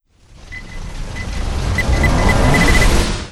exploder_big_windup.wav